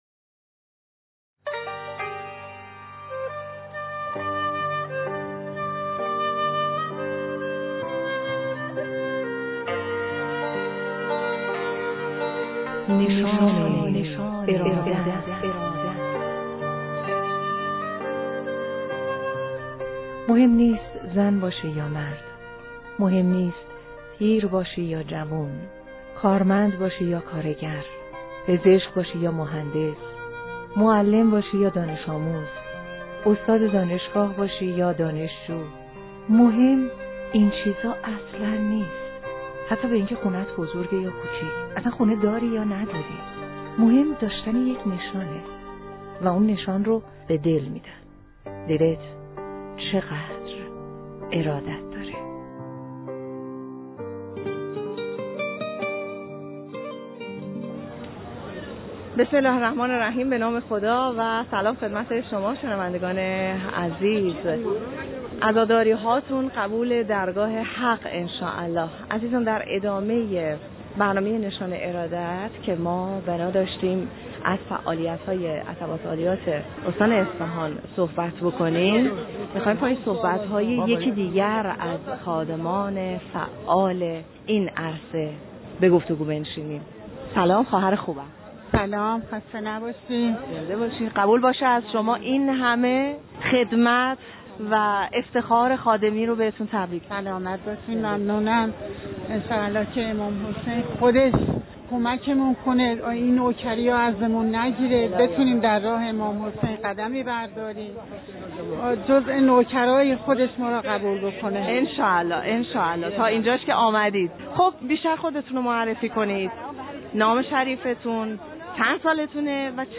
برنامه رادیویی نشان ارادت